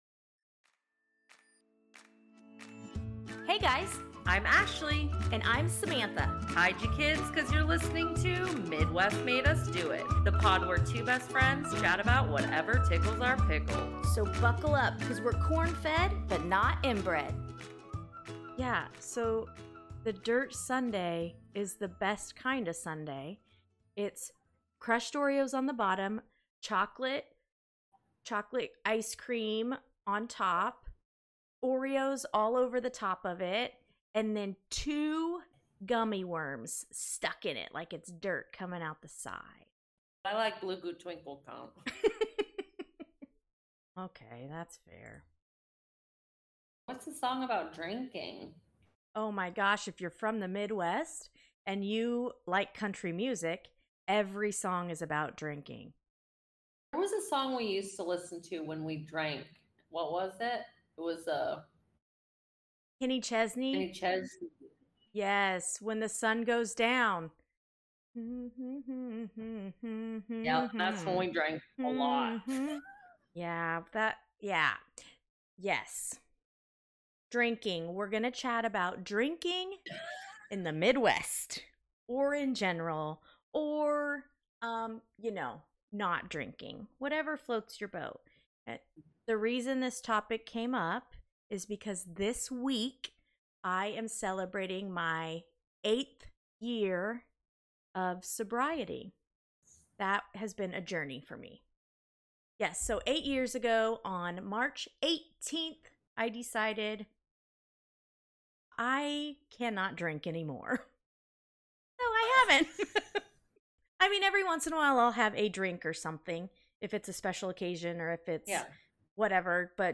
The pod where two best friends chat about whatever tickles their pickle.